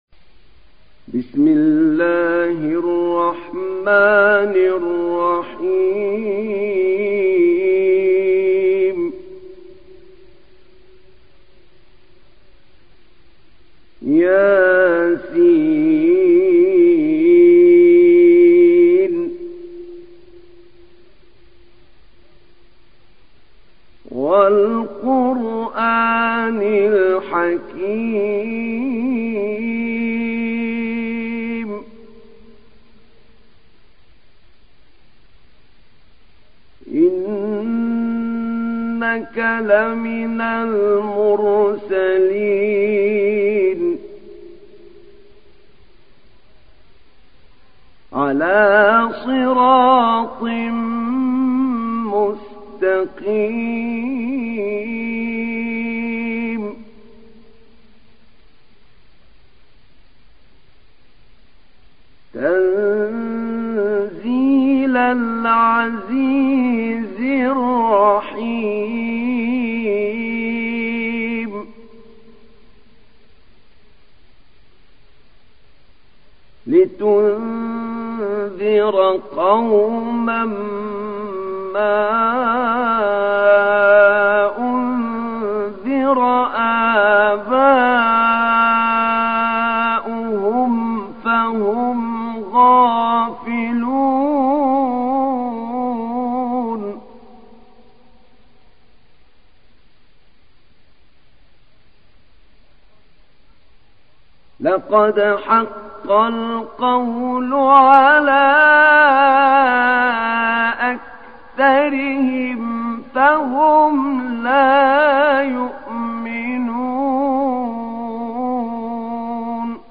Surat Yasin Download mp3 Ahmed Naina Riwayat Hafs dari Asim, Download Quran dan mendengarkan mp3 tautan langsung penuh